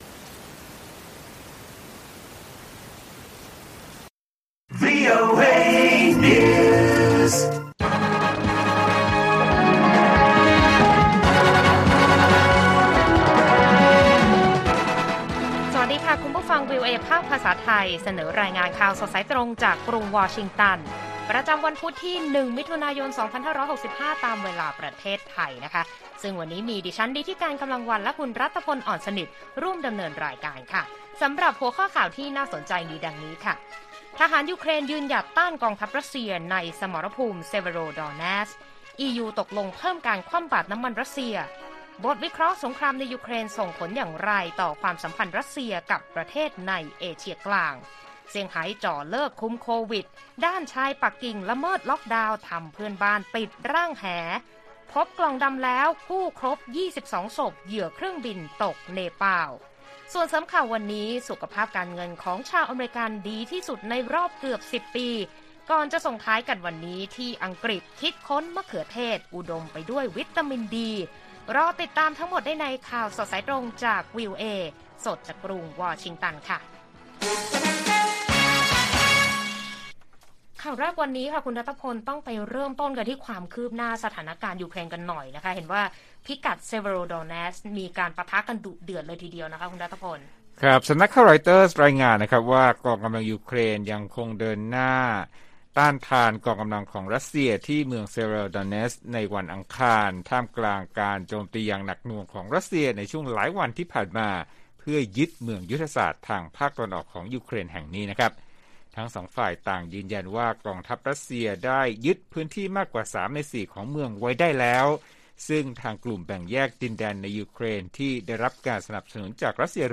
ข่าวสดสายตรงจากวีโอเอ ไทย พุธ ที่ 1 มิ.ย. 2565